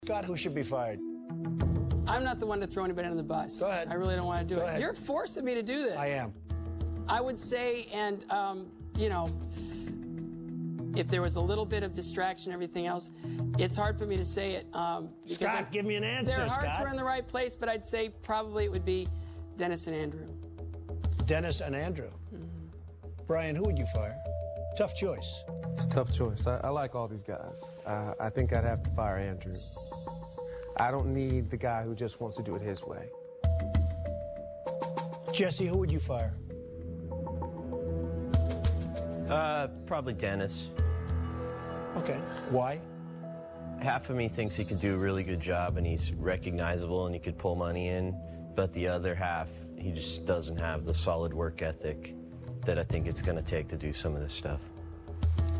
在线英语听力室影视剧中的职场美语 第24期:艰难选择的听力文件下载,《影视中的职场美语》收录了工作沟通，办公室生活，商务贸易等方面的情景对话。